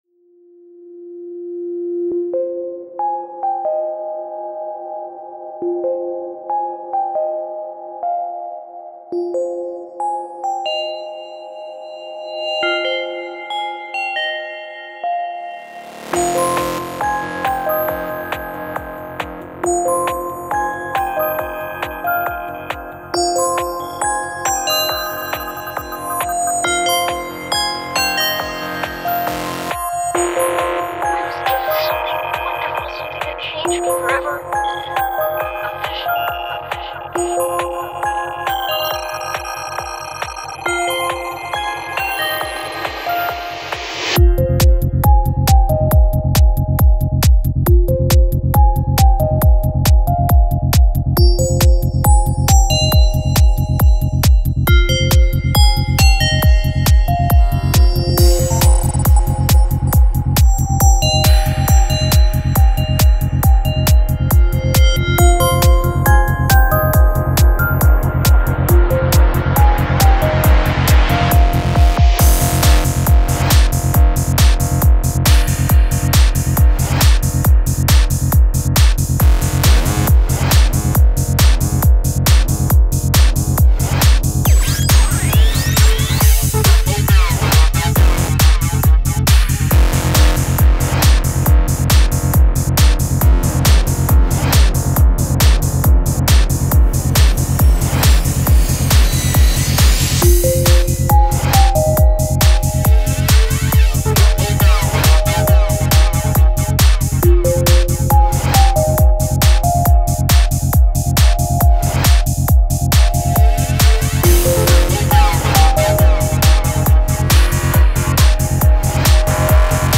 сочетая элементы электроники и психоделического транса